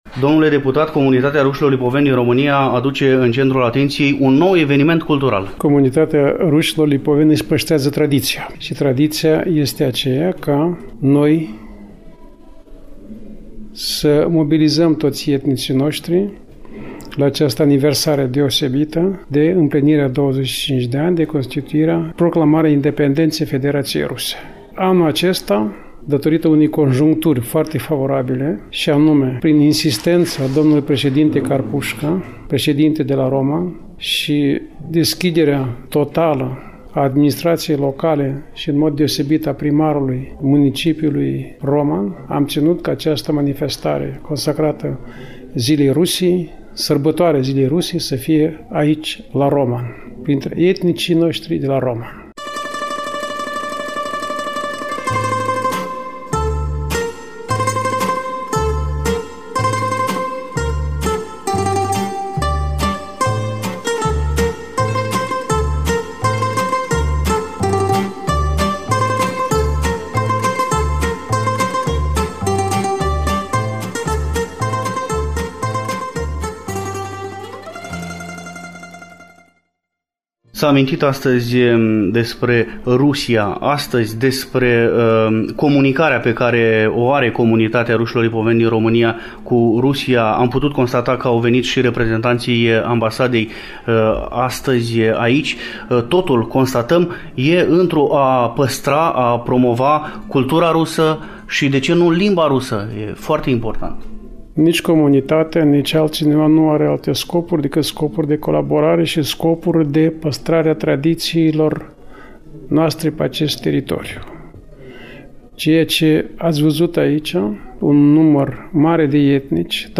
Ultimul invitat al emisiunii este d-l. deputat Miron Ignat, președinte al Comunității Rușilor Lipoveni din România. L-am întâlnit, nu demult, la Roman, Neamț, atunci când a fost serbată Ziua Federației Ruse (12 iunie).